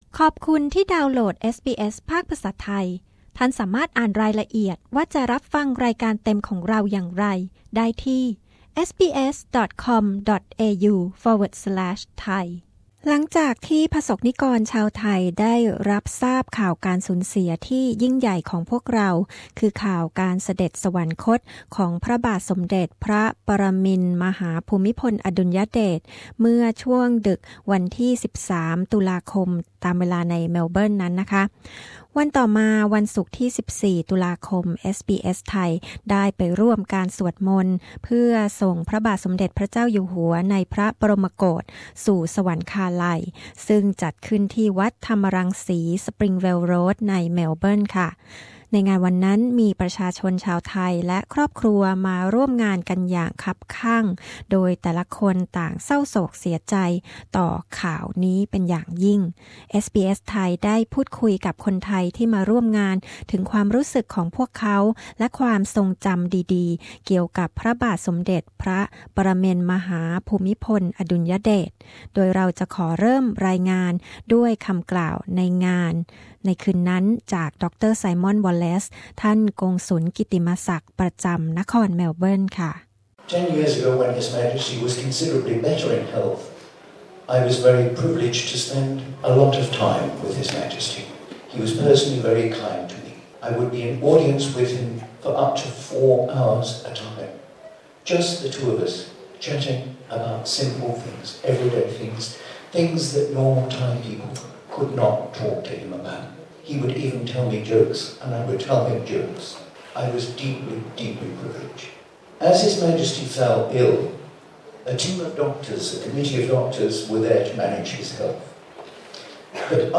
ท่านกงสุลกิตติมศักดิ์ประจำนครเมลเบิร์นเล่าถึงประสบการณ์ได้เข้าเฝ้า ฯ ใกล้ชิด ขณะที่ชาวไทยในเมลเบิร์น แสดงความรักและศรัทธาต่อพระองค์ท่านจากก้นบึ้งของหัวใจ ในพิธีถวายความอาลัยแด่พระบาทสมเด็จพระปรมินทรมหาภูมิพลอดุลยเดช พร้อม ในวันศุกร์ ที่ 14 ตุลาคม วัดธรรมรังษี นครเมลเบิร์น